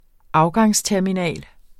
Udtale [ ˈɑwgɑŋs- ]